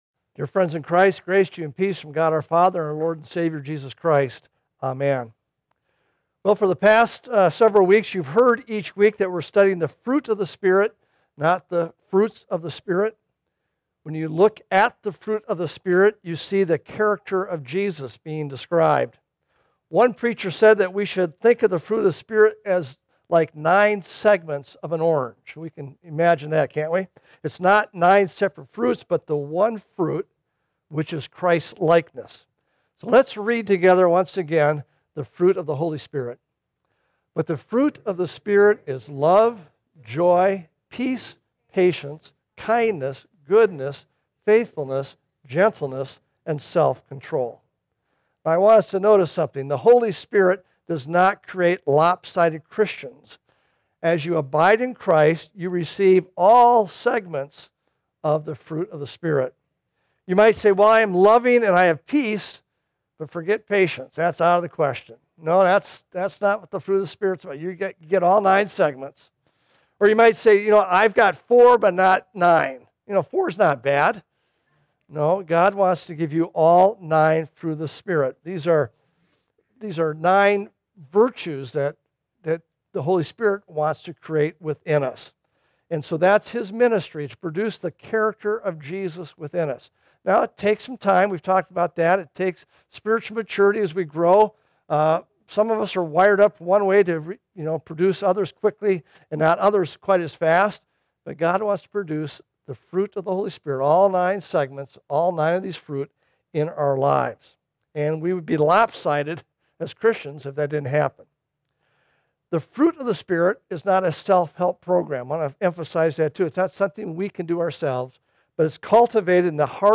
Sermons – Desert Hope Lutheran Church